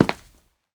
Step6.ogg